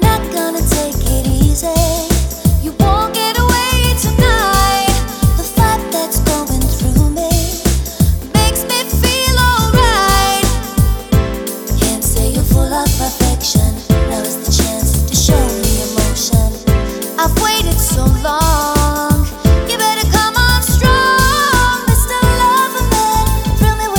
Album Version for Solo Male Pop (1990s) 5:40 Buy £1.50